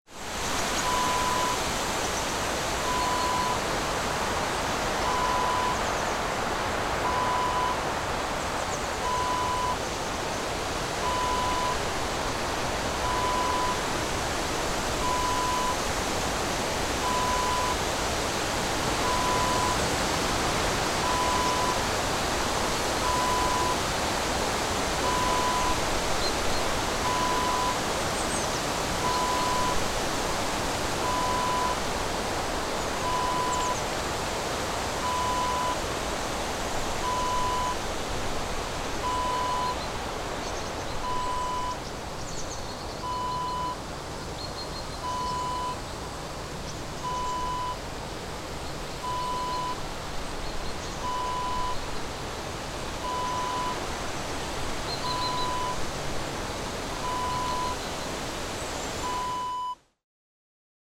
Windy Forest Ambience Wav Sound Effect #2
Description: Windy forest background ambience. Birds sing and a strong wind blows through the trees.
Properties: 48.000 kHz 24-bit Stereo
A beep sound is embedded in the audio preview file but it is not present in the high resolution downloadable wav file.
Keywords: forest, background, ambience, bird, birds, sing, singing, chirp, chirping, wind, windy, breeze, blow, trees
windy-forest-ambience-preview-2.mp3